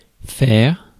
Ääntäminen
France: IPA: [fɛʁ]